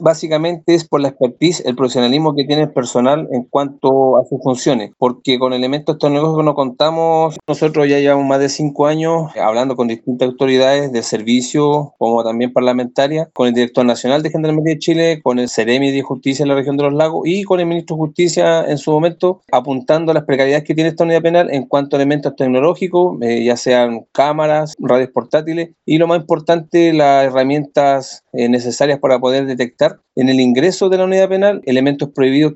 señaló en conversación con Radio Bío Bío que la falta de tecnología especializada dificulta la detección de elementos prohibidos en el penal.